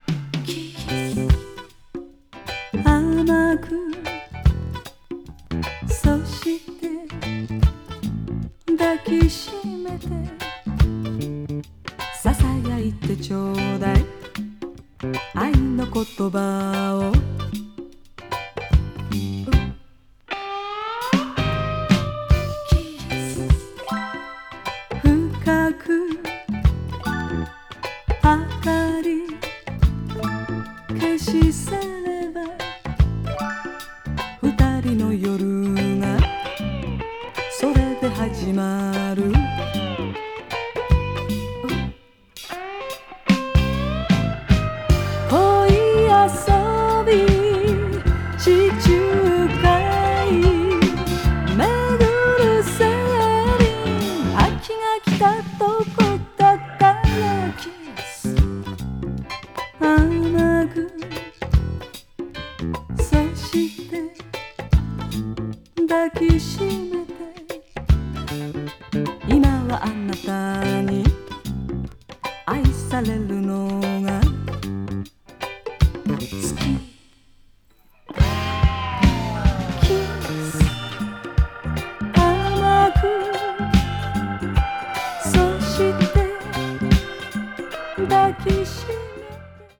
adult pop   japanese pop   kayohkyoku   mellow groove